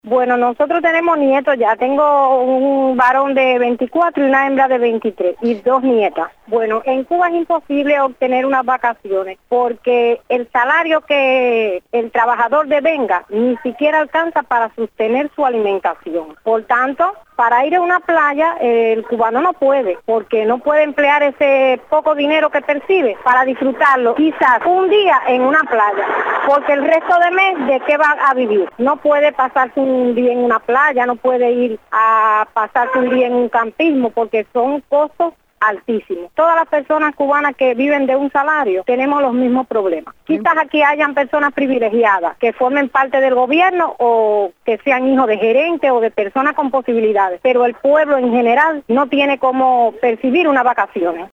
Una abuela explica por qué es imposible tener vacaciones con un salario